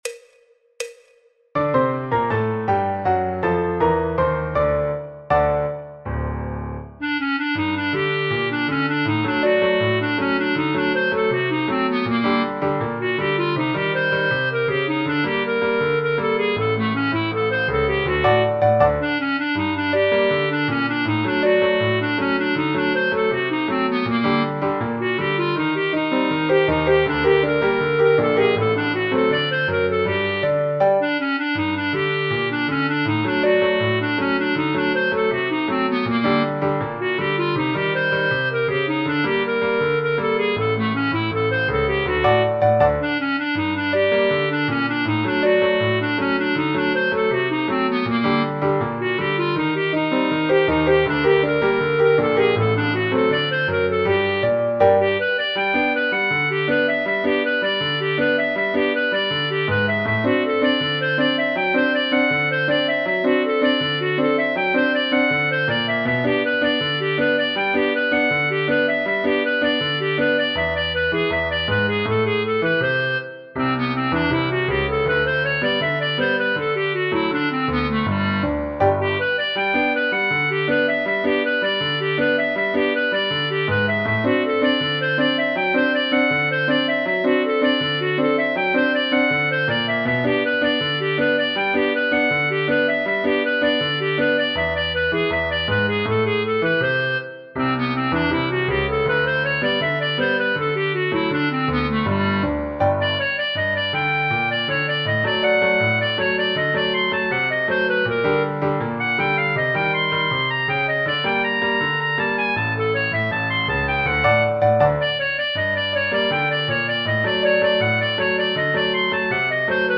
Choro, Jazz, Popular/Tradicional